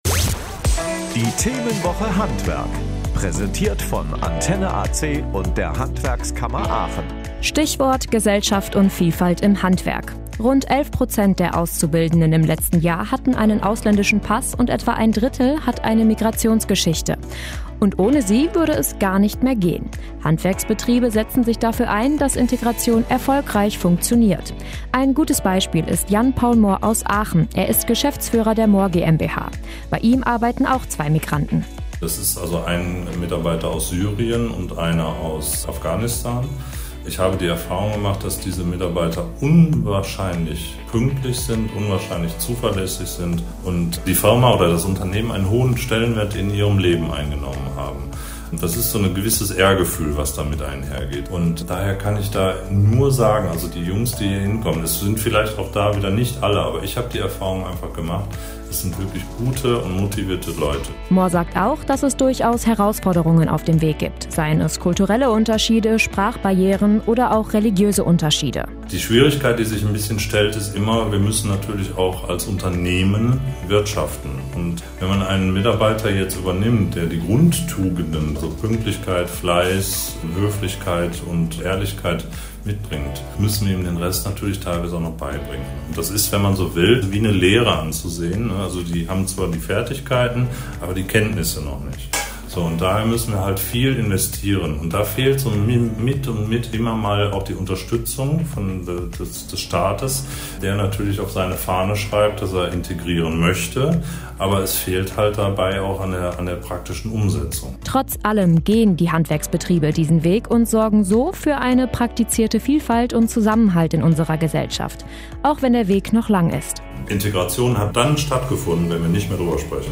Radiobeiträge: Integration im Handwerk